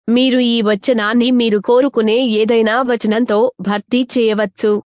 Professionelle Sprachausgabe zum Vorlesen und Vertonen beliebiger Texte
Professionelle, natürlich klingende männliche und weibliche Stimmen in vielen Sprachen, die kaum mehr von einem menschlichen Sprecher zu unterscheiden sind.